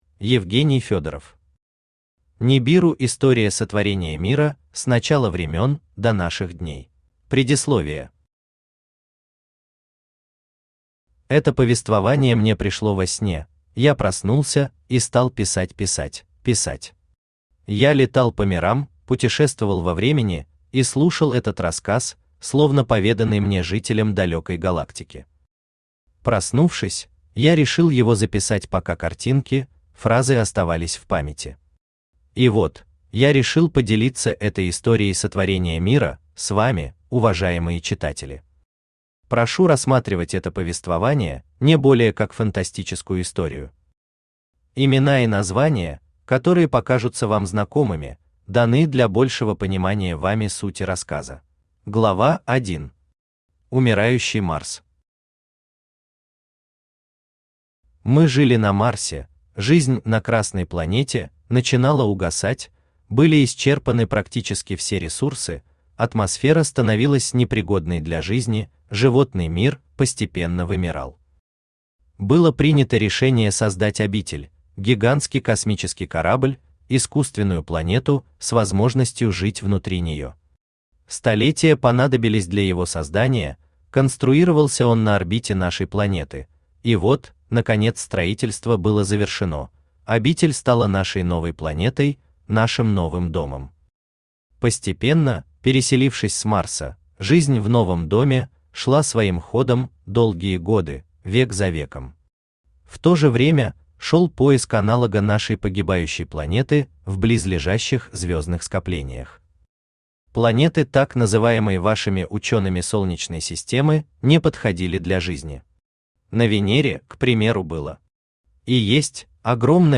Аудиокнига Нибиру | Библиотека аудиокниг
Aудиокнига Нибиру Автор Евгений Федоров Читает аудиокнигу Авточтец ЛитРес.